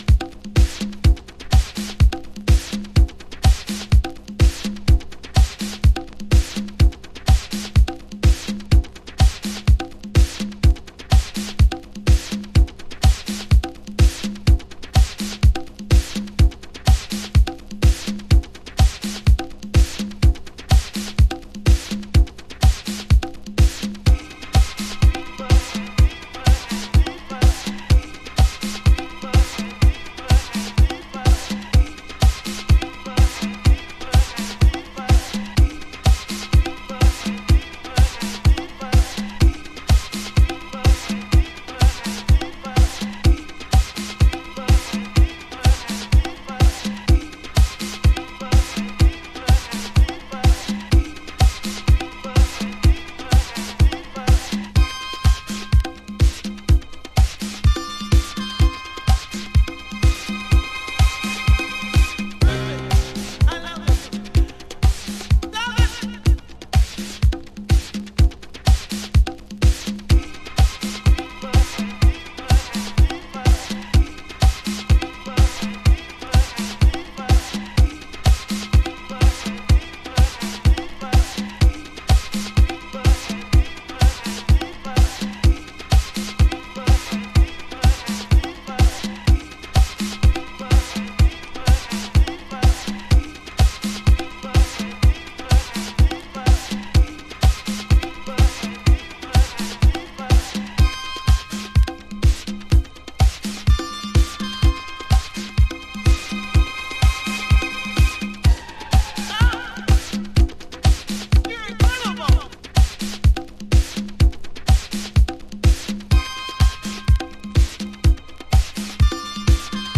House / Techno
パワフルなディスコハウス。